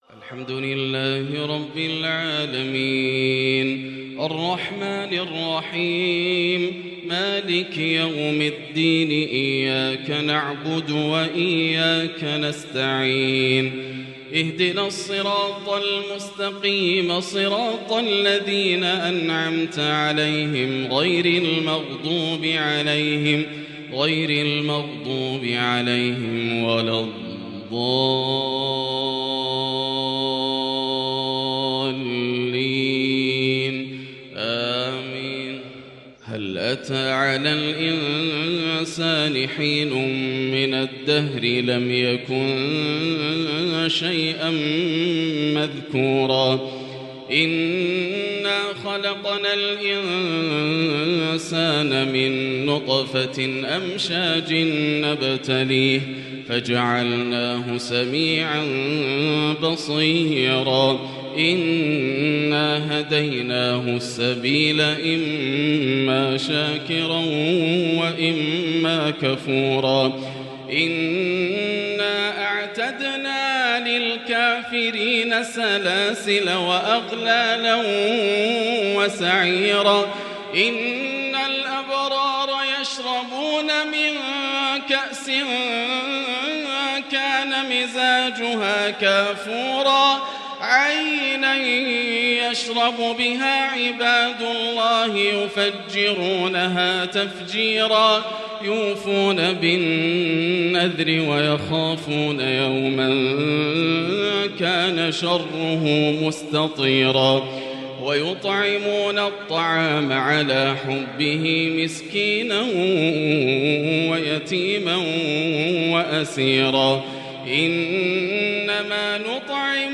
“وأيوب إذ نادى ربه” تلاوة عجيبة بتحبير مذهل لغريد الحرم د.ياسر الدوسري > مقتطفات من روائع التلاوات > مزامير الفرقان > المزيد - تلاوات الحرمين